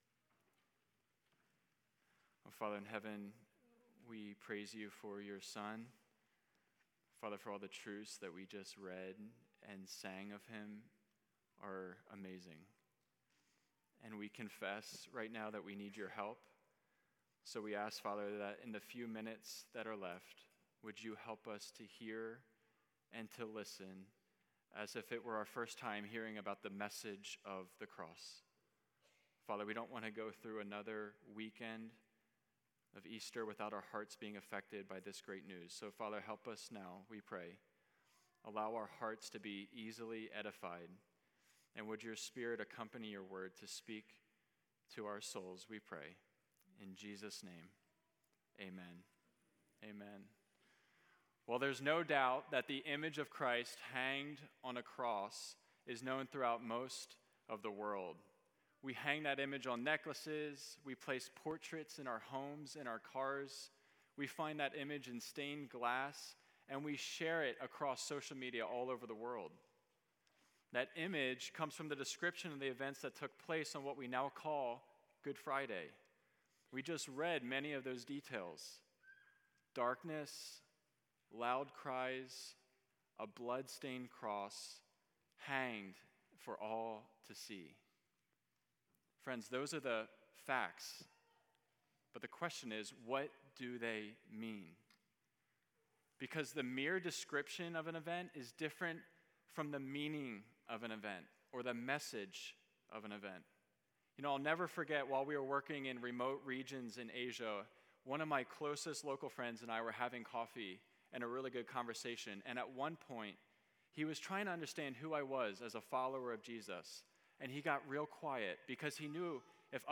Sermon Audio | University Baptist Church